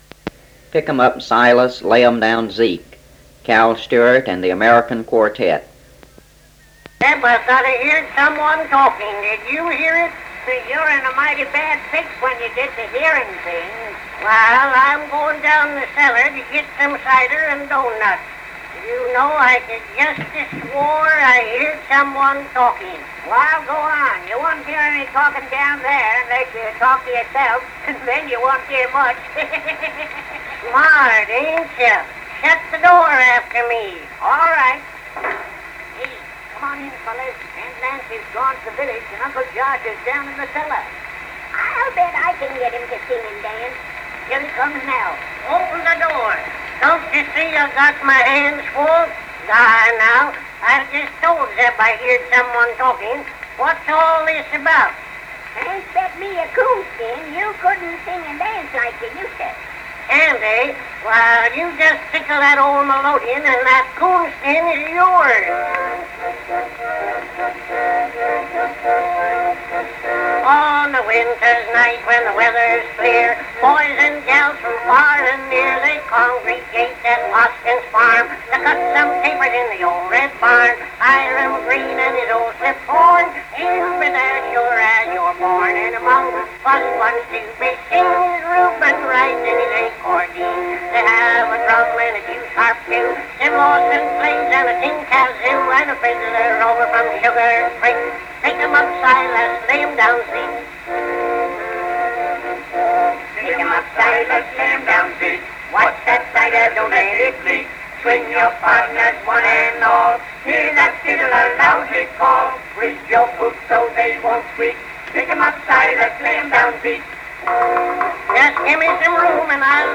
comedy song